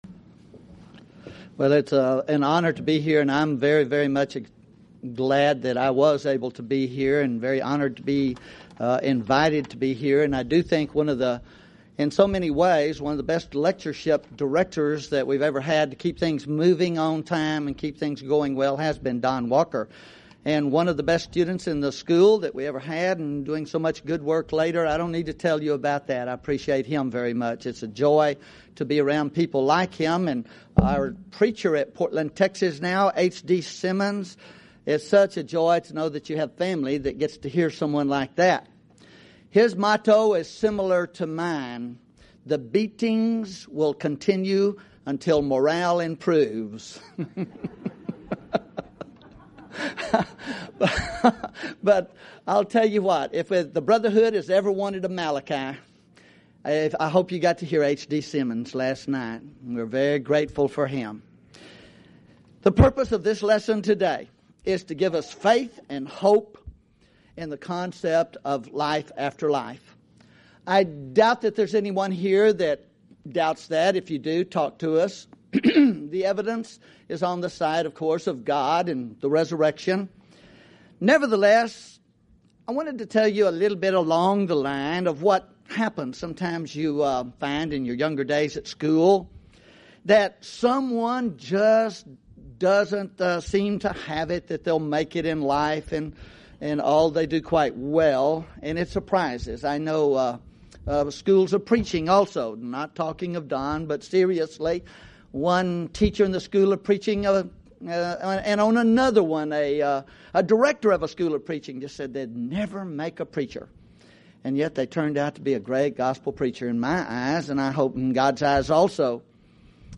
Event: 2003 Annual Shenandoah Lectures
lecture